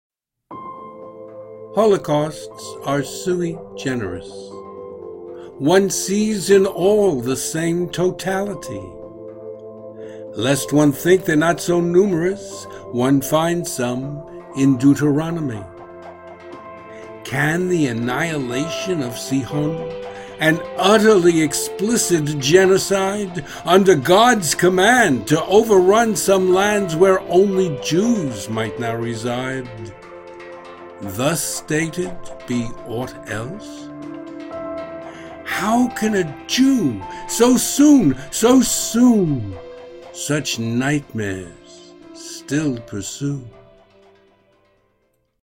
Video and Audio Music: